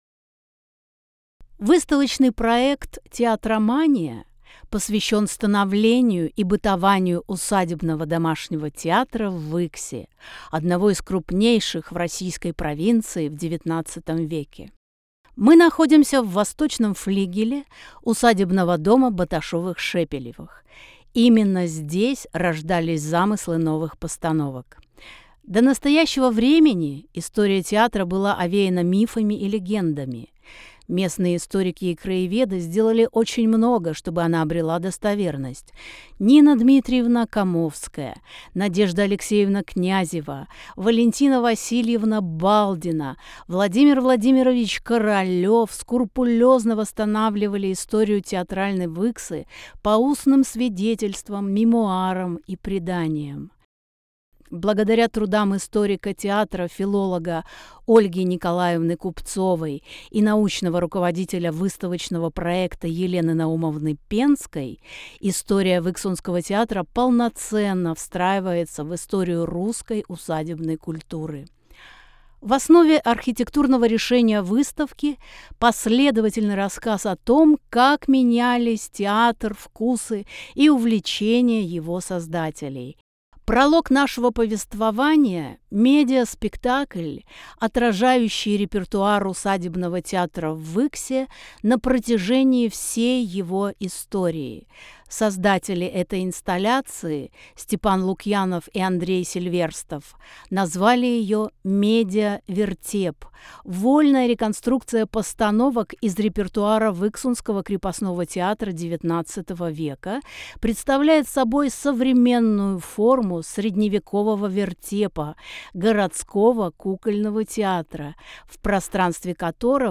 Тифлокомментарии к экспонатам выставки
Аудиогид. 1 этаж. Преамбула выставки Аудиогид. 1 этаж.